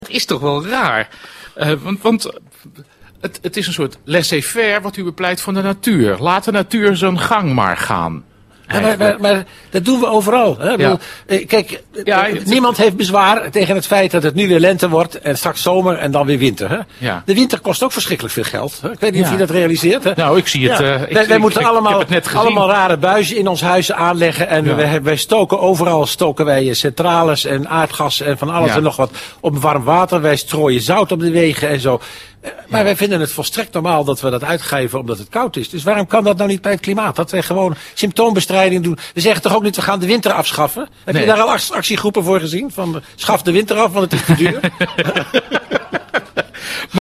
Dus verspil geen geld aan onzinnige milieumaatregelen, maar pak alleen de symptomen aan van wat er misloopt door de klimaatsveranderingen, zo betoogt hij in het radioprogramma 747live.